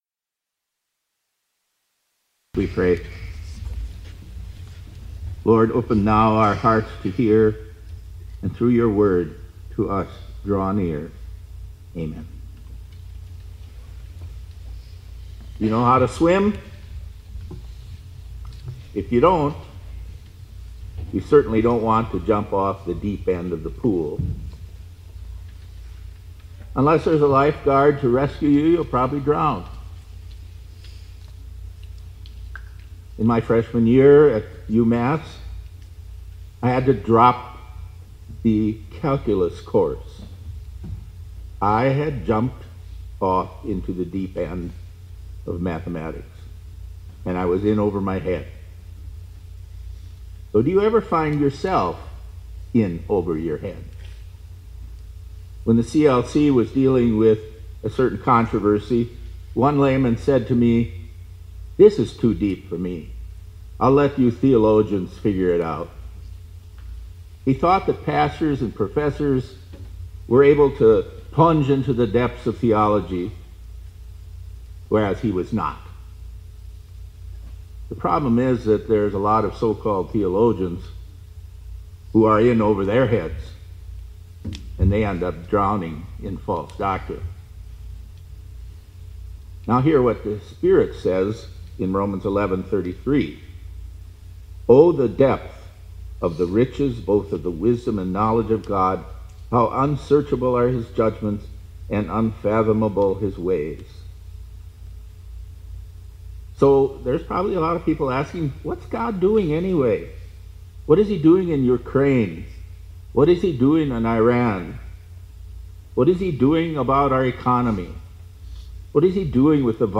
2026-04-21 ILC Chapel — Christ is Our Spiritual Lifeguard